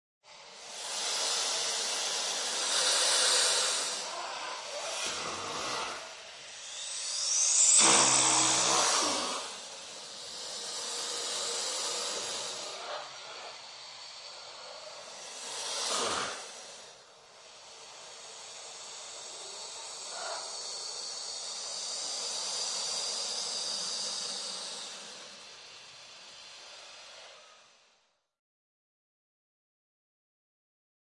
SFX可怕的绿皮蛇叫声音效下载
SFX音效